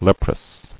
[lep·rous]